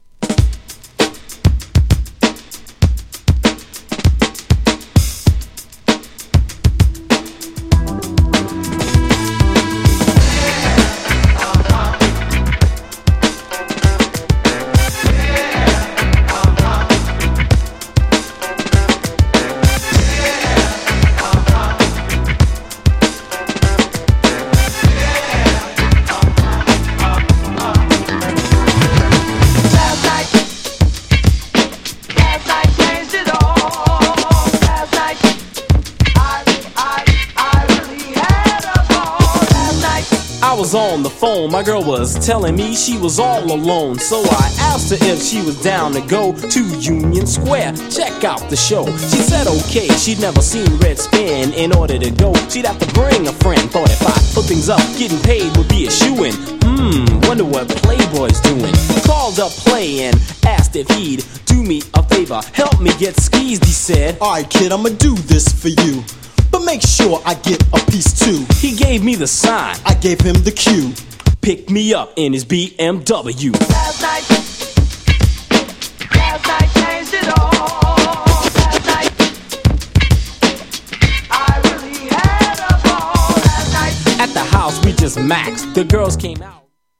80年代後期、NEW JACKやGO-GO等の流行のエッセンスを取り入れ流行ったRAPユニットのデビューアルバム!!
GENRE Hip Hop
BPM 106〜110BPM